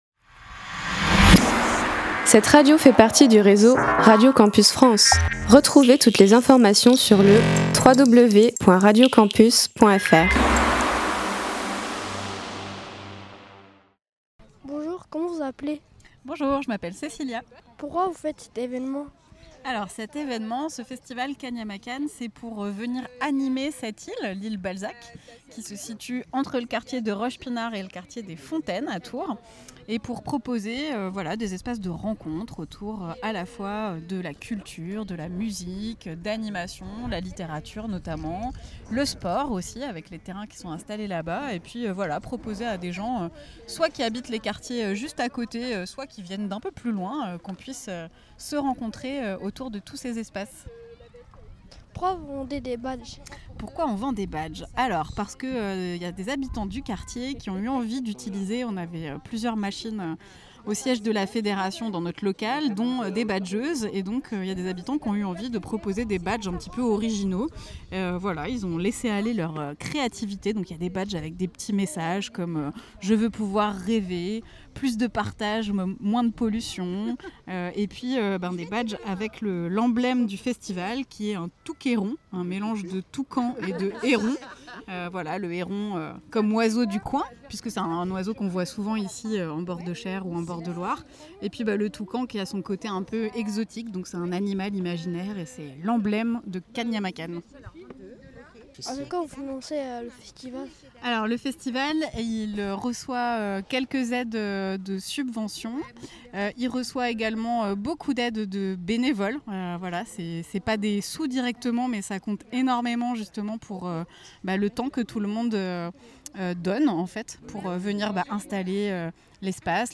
On a pu se promener avec nos micros sur le festival et réaliser quelques interviews que nous vous proposons ici en podcasts.
itw_ligueenseignement.mp3